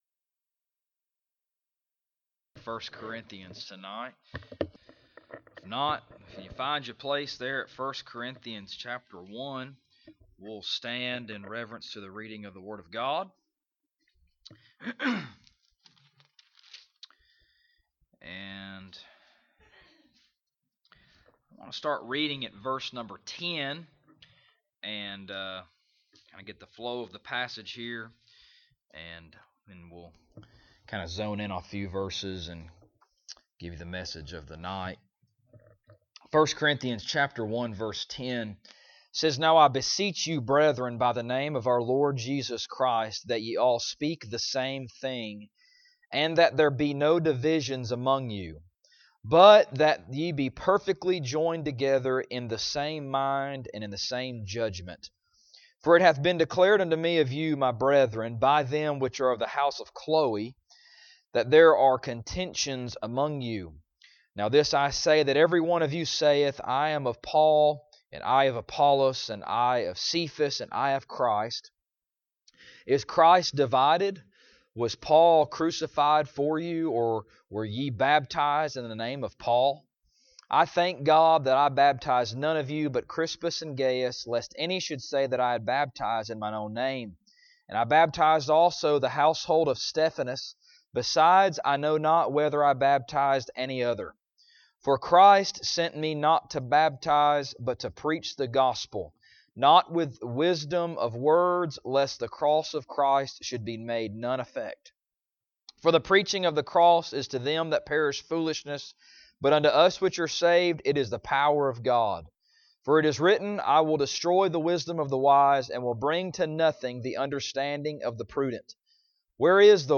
1 Corinthians Passage: 1 Corinthians 1:10-25 Service Type: Sunday Evening « Knowing to Know